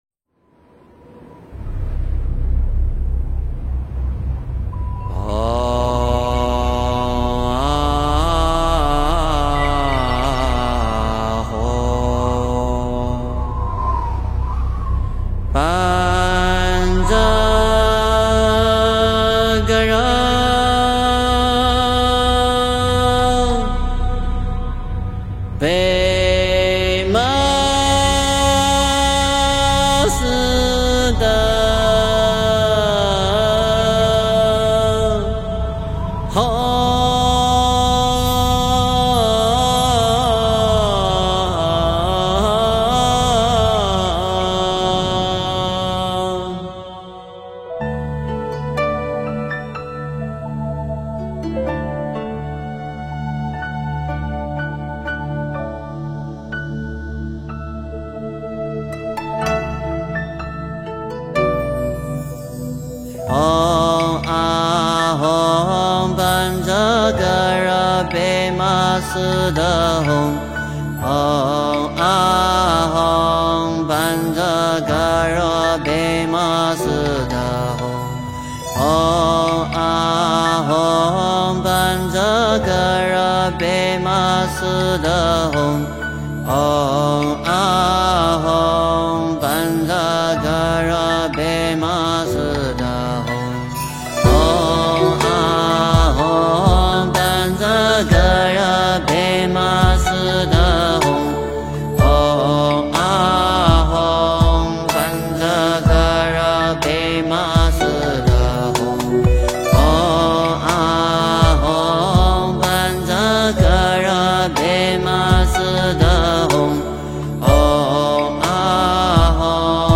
诵经
佛音 诵经 佛教音乐 返回列表 上一篇： 观音赞 下一篇： 绿度母心咒 相关文章 空谷禅思（纯音乐）--未知 空谷禅思（纯音乐）--未知...